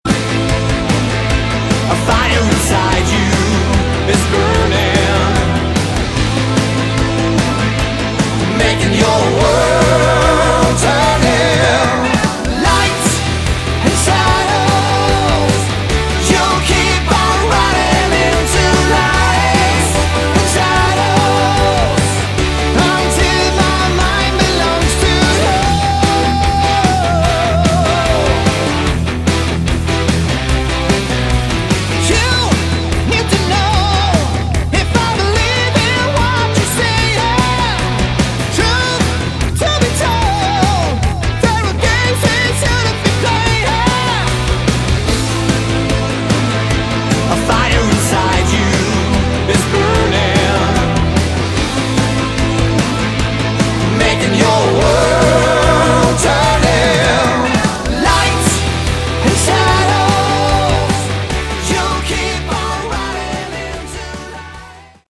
Category: Hard Rock
guitar
vocals
bass
drums